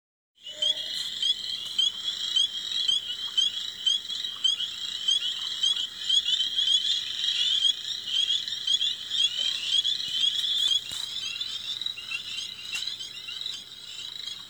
These tiny frogs may only be an inch long, but groups of them together can produce very loud choruses that drown out any other sounds around them!
The tiny Spring Peeper hides in marshes but has a very loud call.
sprpeep.m4a